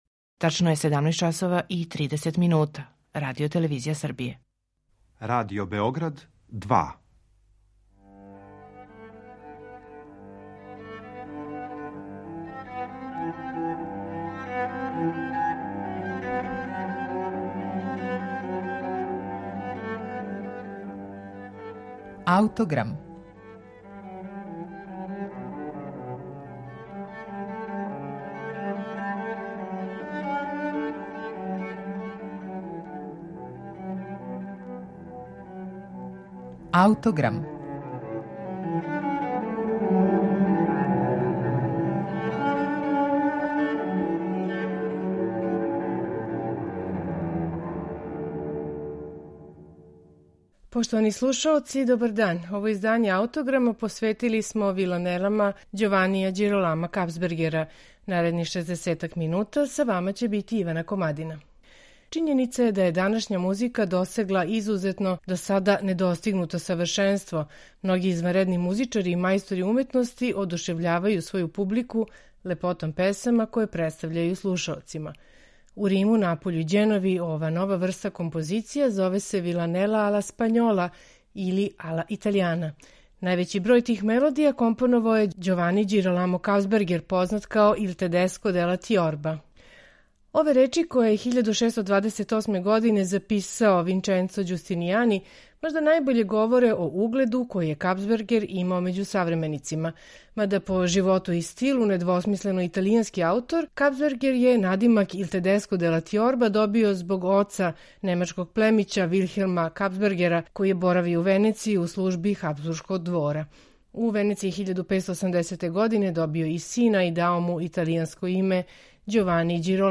сопран
тенори
барокна харфа, теорба, барокна гитара